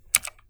Οι πράσινοι διακόπτες είναι τα κλασικά Switch της Razer, για αυτούς που θέλουν να νιώθουν και να ακούνε το κάθε πάτημα στο πληκτρολόγιό τους.
ΑίσθησηClicky
razer-green-switch-clip.wav